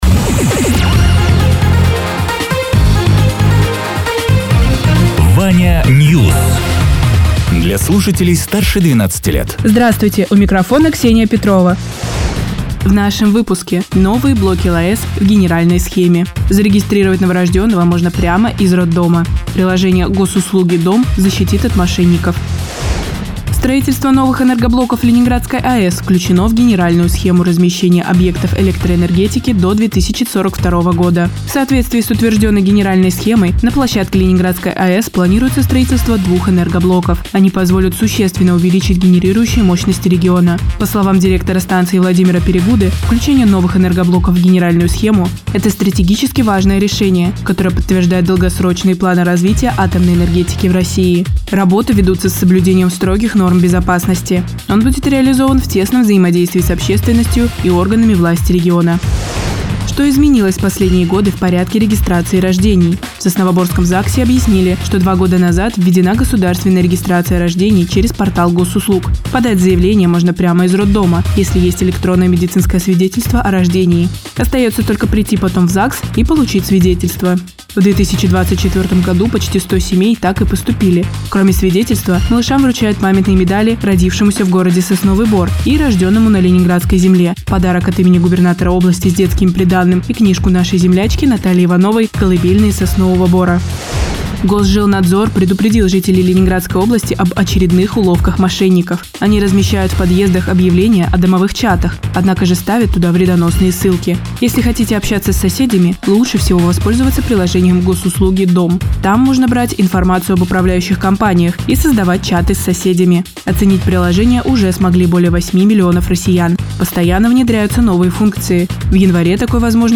Радио ТЕРА 23.01.2025_10.00_Новости_Соснового_Бора